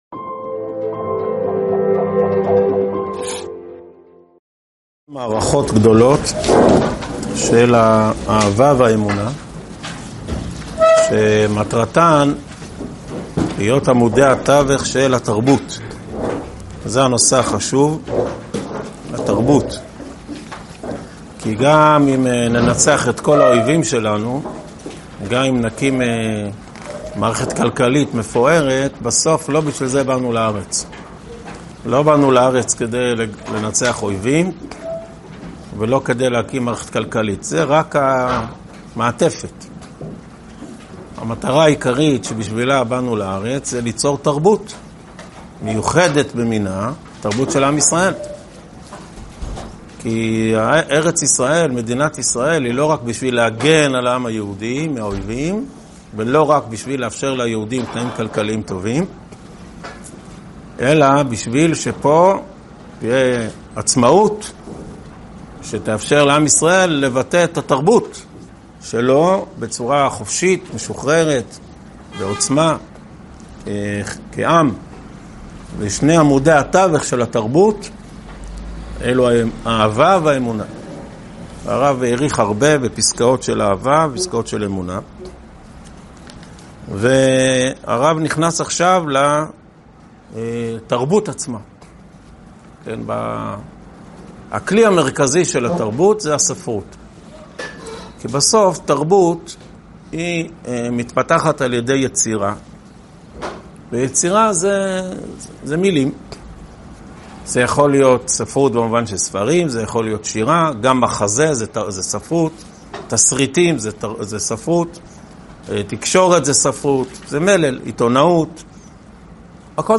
הועבר בישיבת אלון מורה בשנת תשפ"ה.